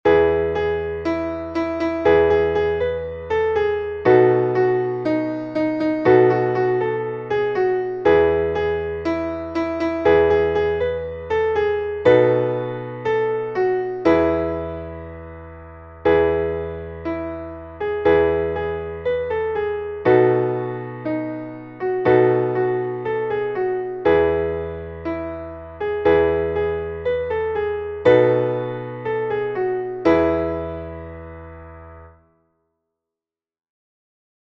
Traditional Spiritual